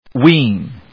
/wíːn(米国英語), wi:n(英国英語)/